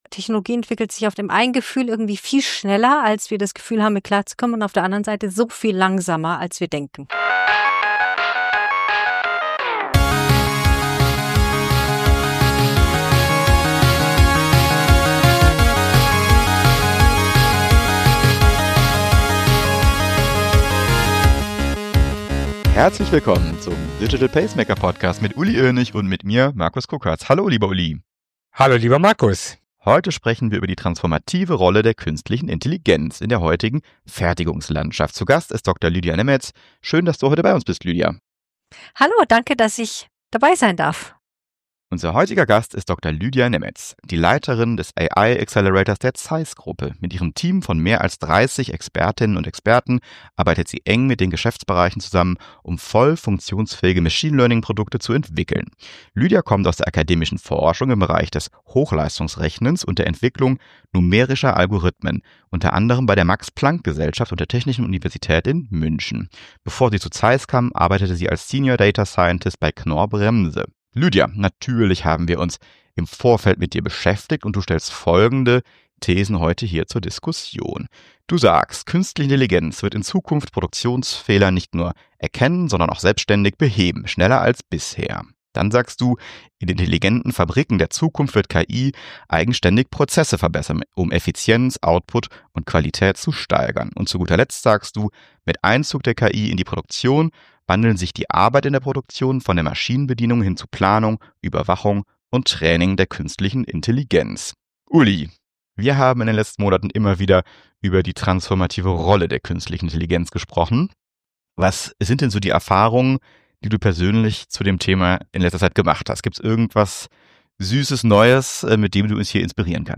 Alles dreht sich um zukunftsweisende Themen und Einblicke von Führungspersönlichkeiten & Vordenkern aus Digital, IT, Tech Innovation, Leadership und Transformation. Im Gespräch erschließt die Runde zusammen neue Perspektiven, gibt Denkanstöße und tauscht persönliche Erfahrungen aus.